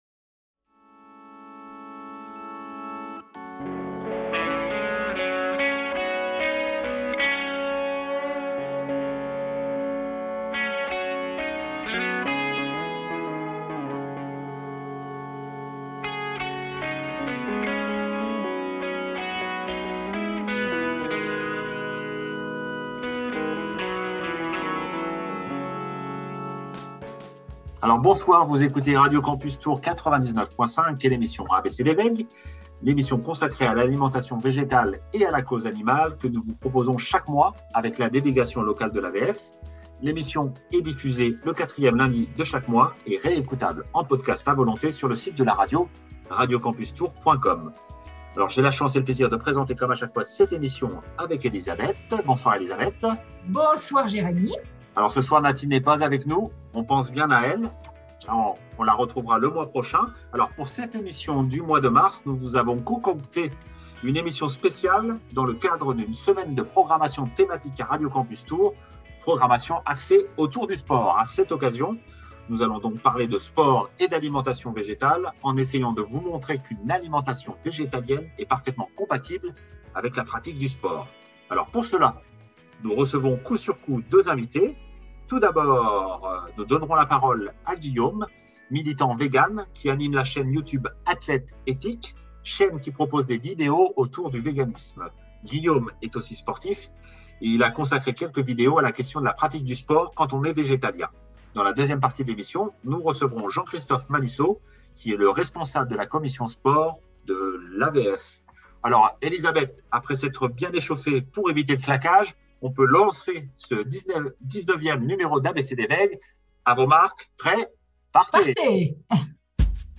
Deux entretiens autour de l’alimentation végétale et de la pratique du sport dans le cadre de la semaine de programmation spéciale « Va y avoir du sport! » sur radio campus Tours.